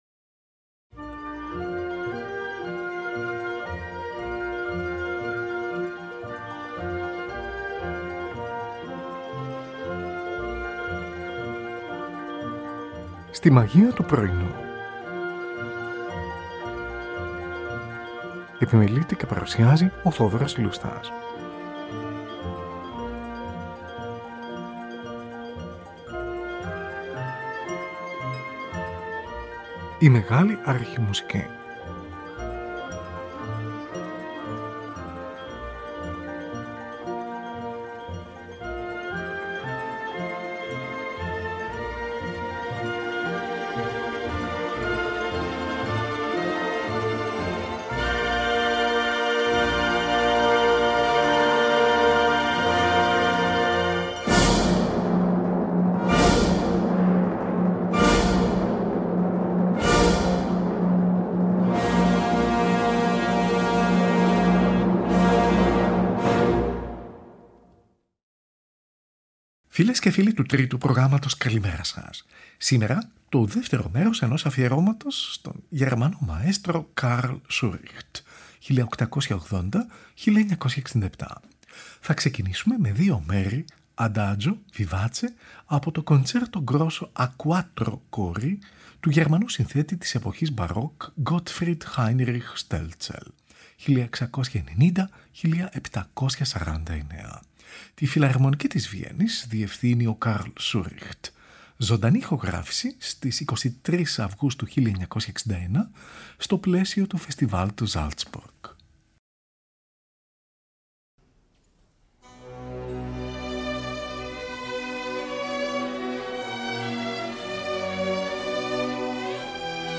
Τη Φιλαρμονική της Βιέννης διευθύνει ο Carl Schuricht. Ζωντανή ηχογράφηση στις 23 Αυγούστου 1961, στο πλαίσιο του Φεστιβάλ του Salzburg.
Την Ορχήστρα της Ραδιοφωνίας της Στουτγάρδης διευθύνει ο Carl Schuricht. Ζωντανή ηχογράφηση της Ραδιοφωνίας της Στουτγάρδης, στις 23 ή 24 Οκτωβρίου 1955.